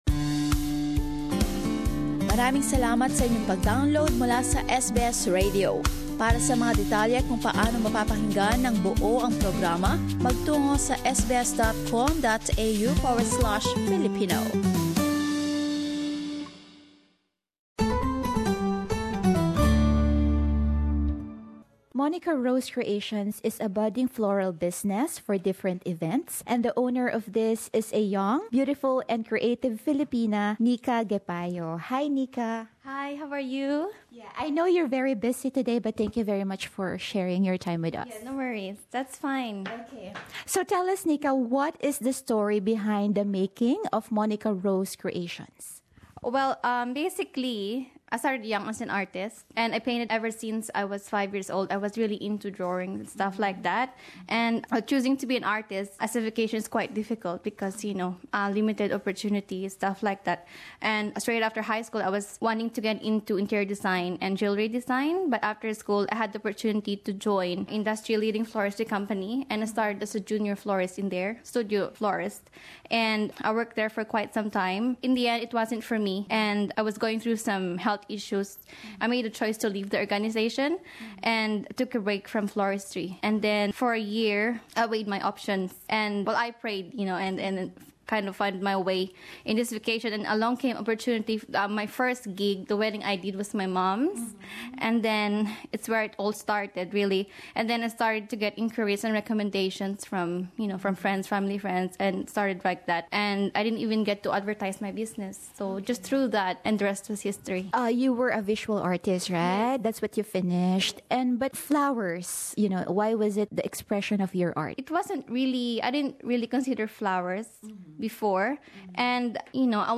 Alamin pa ang kanyang kwento sa panayam na ito.